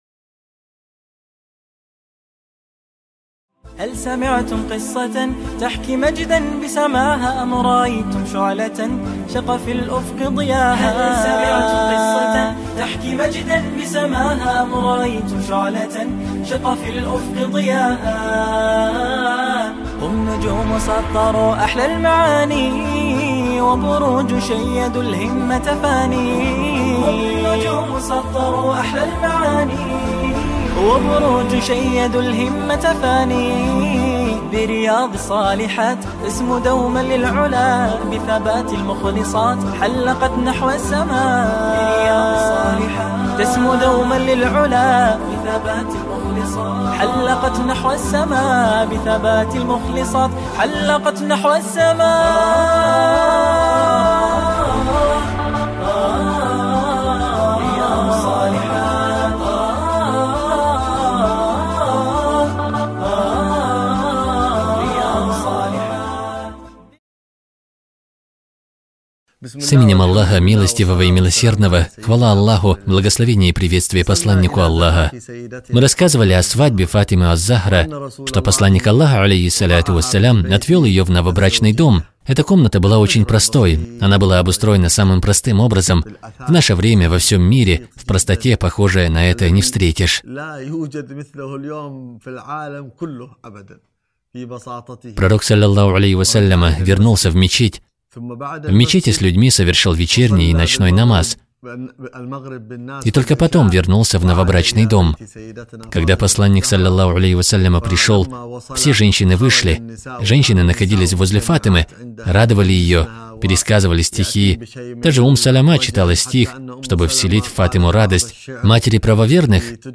Цикл лекций